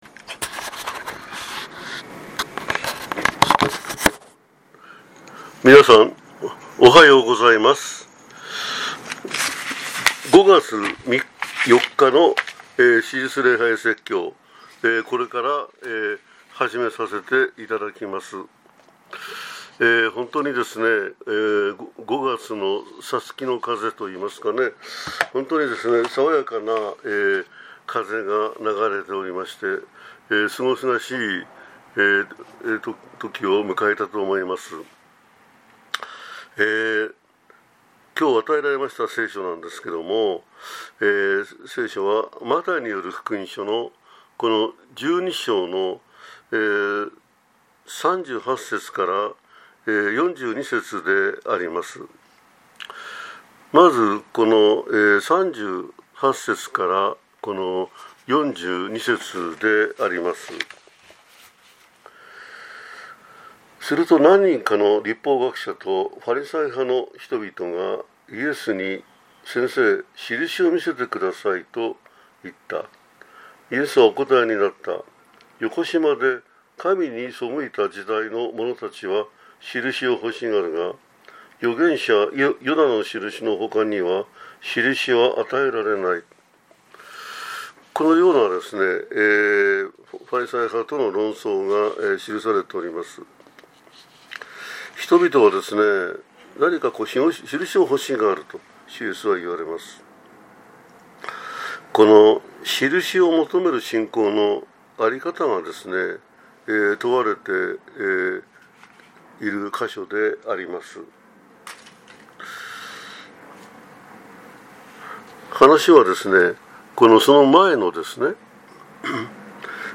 2025年5月4日（復活節第3主日）